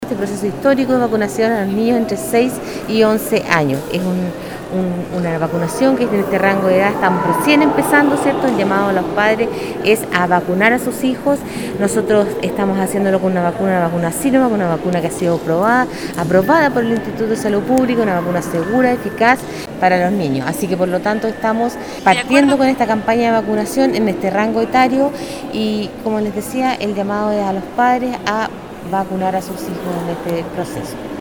Marcela Cárdenas, Seremi de Salud (s) dijo que el proceso en este rango de edad tiene un carácter histórico, que permitirá asegurar una mayor inmunización en la comunidad.